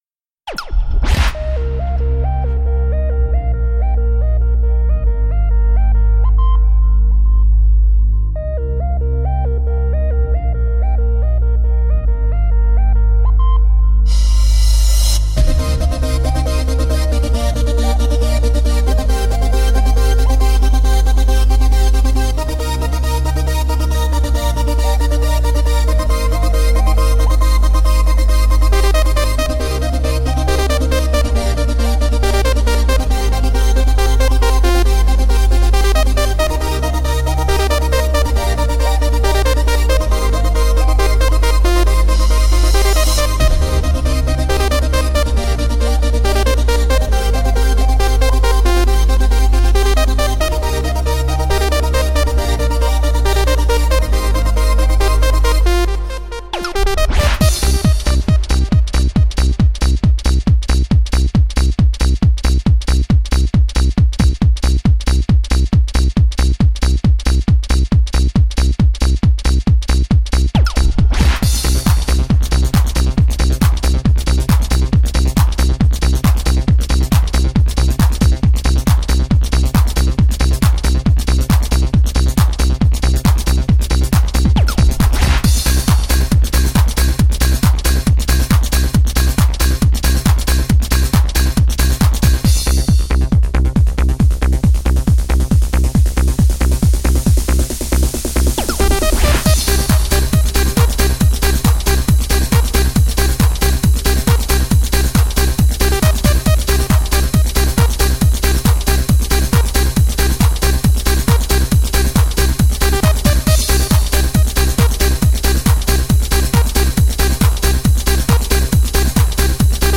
Жанр: Dance/Electronic